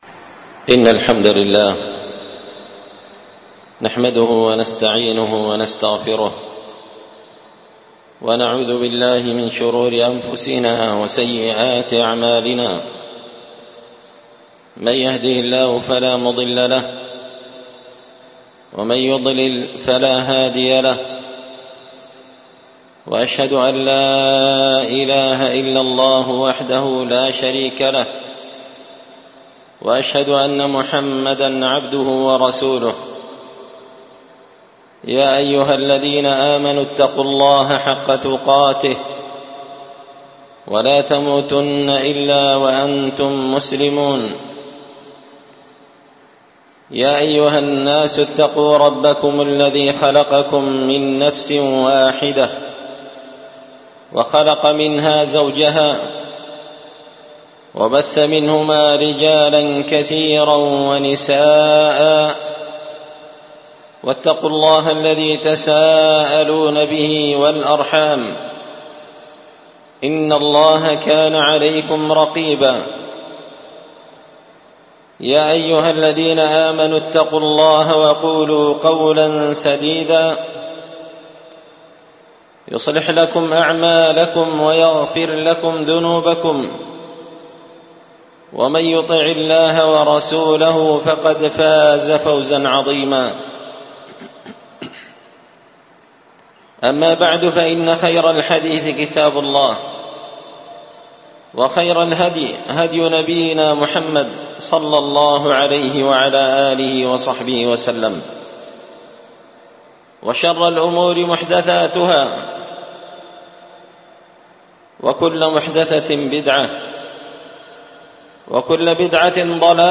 خطبة جمعة بعنوان:
ألقيت هذه الخطبة في مسجد الفرقان قشن-المهرة-اليمن